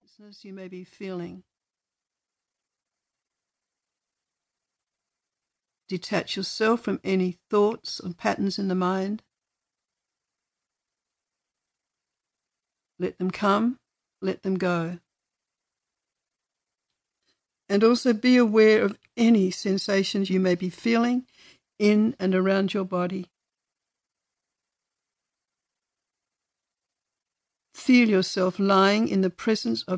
Yoga Nidra (guided relaxation) on the elements progressively leads you deeper into your consciousness.